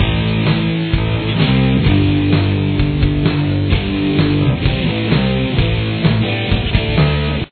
Here’s what the riff sounds like with guitar and bass: